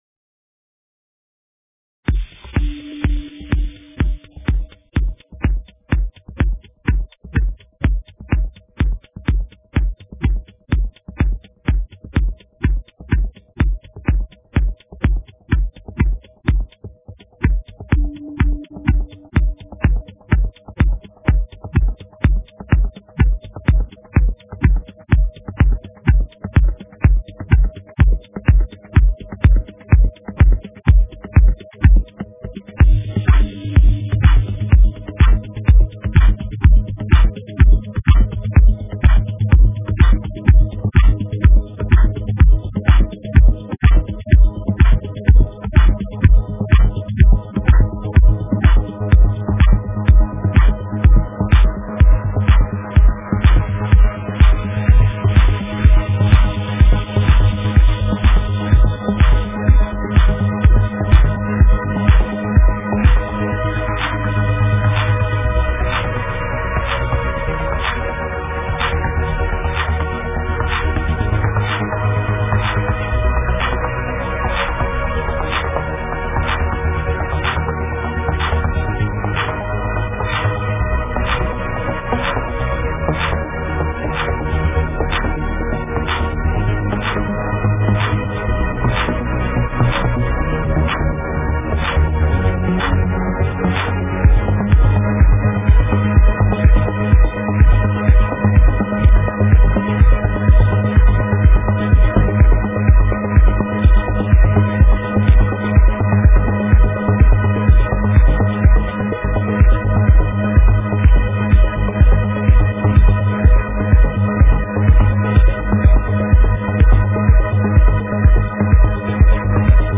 Стиль: Melodic Progressive